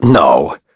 Shakmeh-Vox-Human_Deny.wav